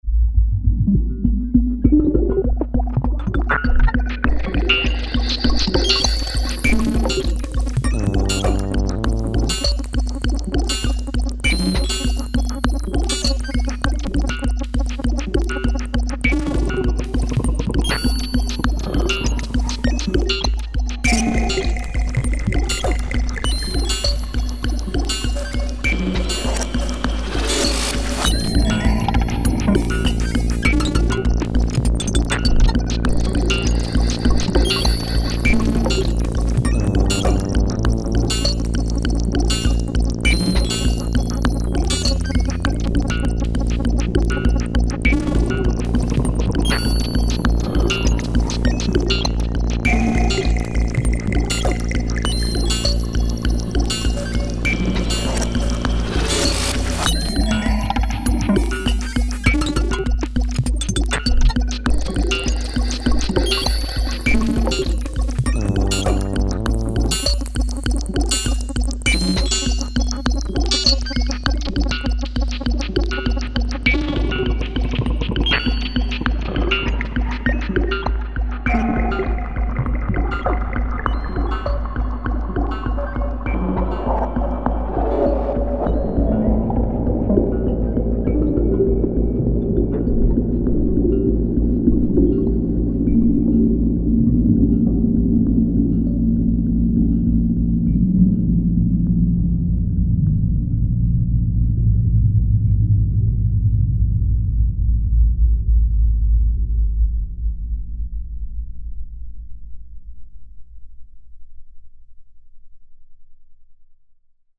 • Accompagnement sonore sur scène.
lerepas-machineavapeur.mp3